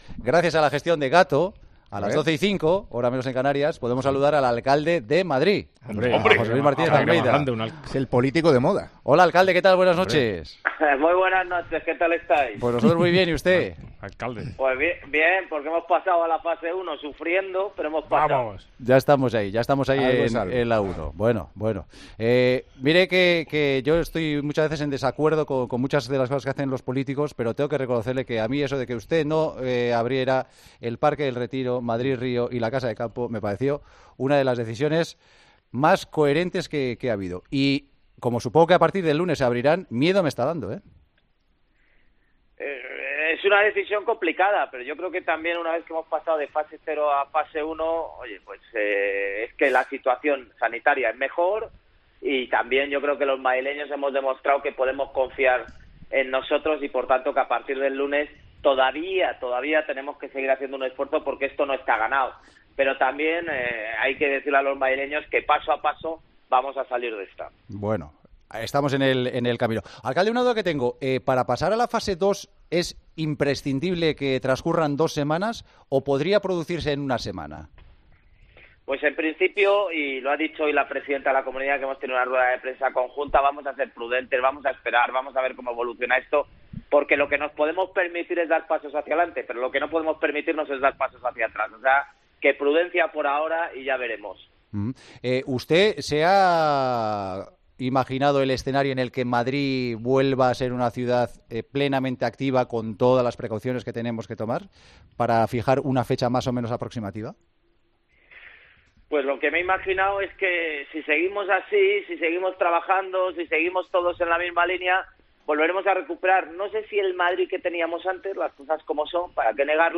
AUDIO - ESCUCHA LA ENTREVISTA AL ALCALDE DE MADRID, JOSÉ LUIS MARTÍNEZ-ALMEIDA, EN EL PARTIDAZO DE COPE